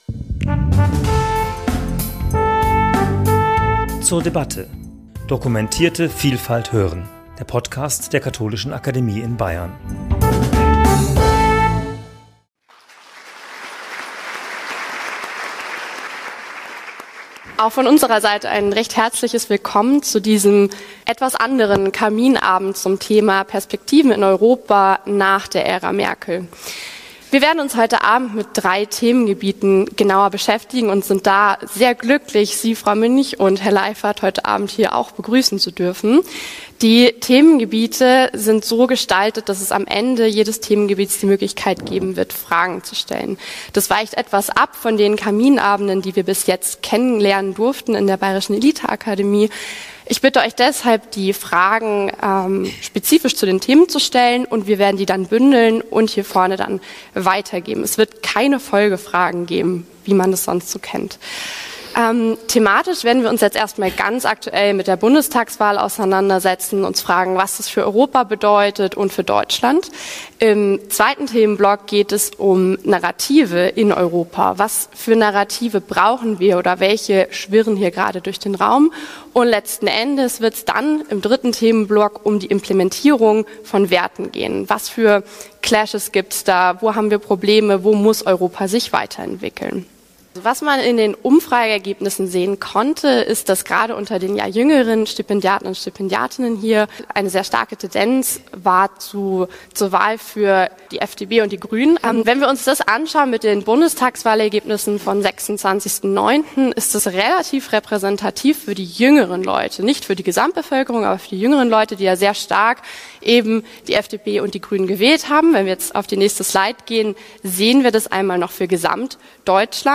Gespräch zum Thema 'Perspektiven für Europa nach der Bundestagswahl' ~ zur debatte Podcast